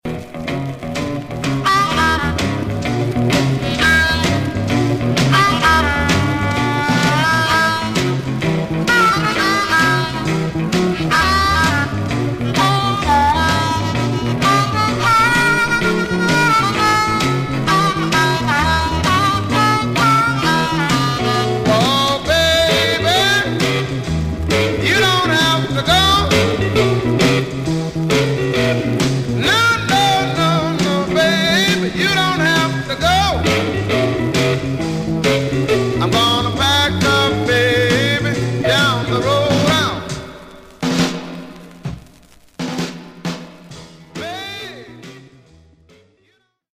Stereo/mono Mono Condition Some surface noise/wear
Rythm and Blues